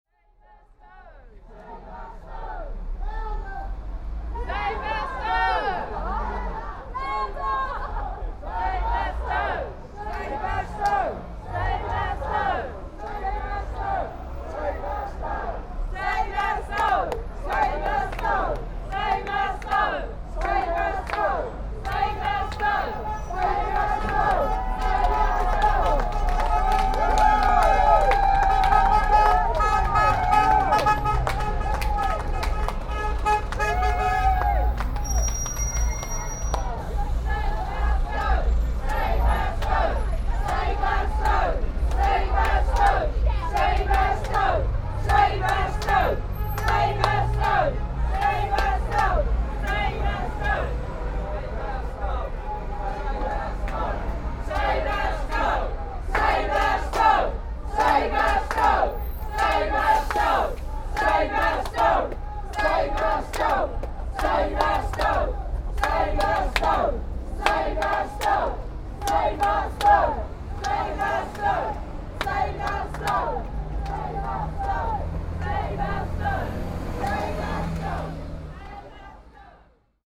Tags: Rallies and demonstrations Rallies and demonstrations clips UK London Rallies and demonstrations sounds